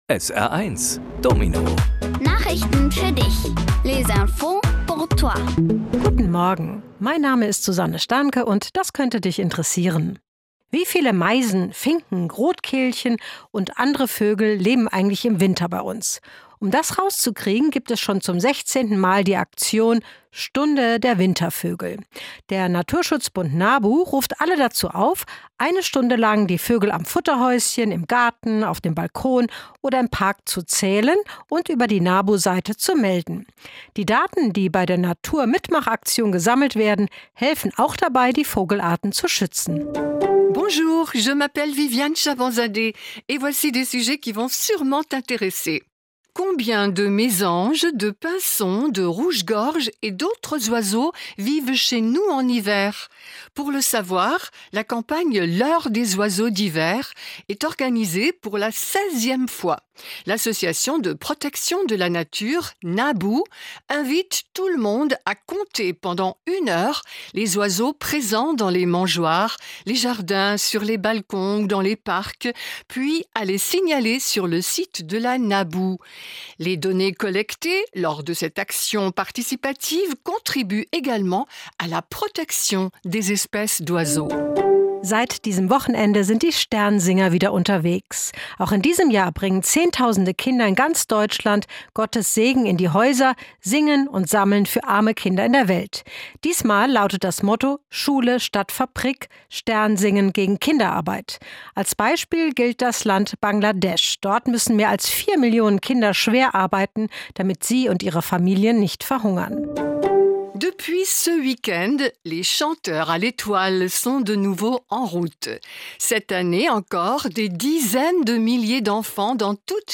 Kindgerechte Nachrichten auf Deutsch und Französisch: 'Stunde der Wintervögel' 2026, Sternsinger sind unterwegs, keine Schulmilch im Saarland, Akkordeon Instrument des Jahres.